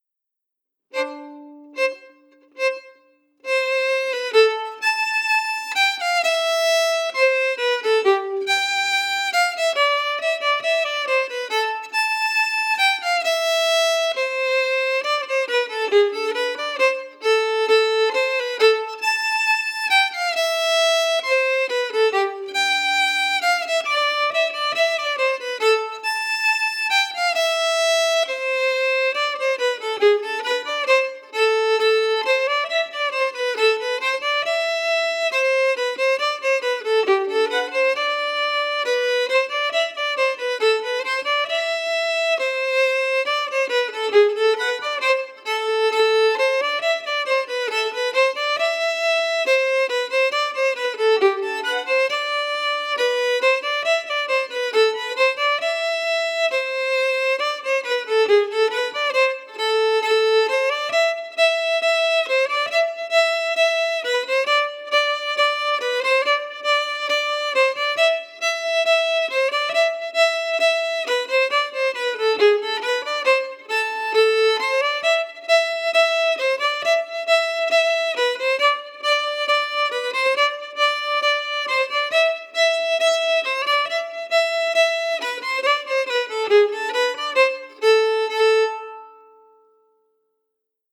Key: Am
Form: Reel
Slow for learning
Red-House-slow-audio.mp3